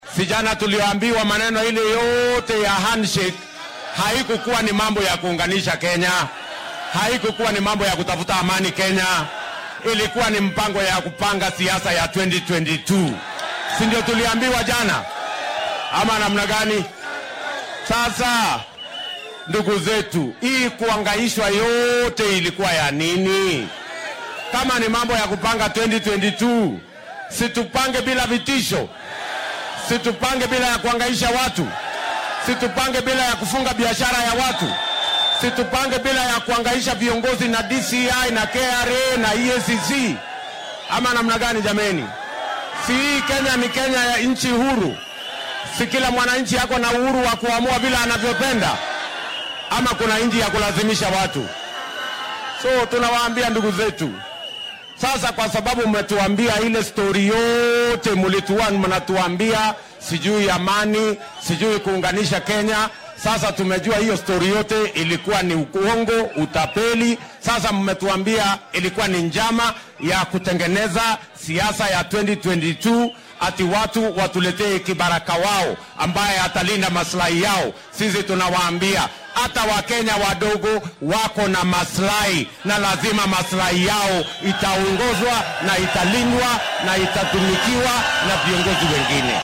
Madaxweyne ku xigeenka dalka William Ruto oo dadweynaha kula hadlay aagga Gachororo ee deegaankaasi ayaa sheegay in heshiiskii madaxweynaha wadanka Uhuru Kenyatta iyo madaxa xisbiga ODM Raila Odinga ee sanadkii 2018-kii aanan looga gol-lahayn mideynta shacabka. Waxaa uu hoosta ka xarriiqay in heshiiskaasi uu ahaa mid ku qotoma qorsho siyaasadeed oo ku aaddan doorashada guud ee bisha Siddeedaad ee sanadkan.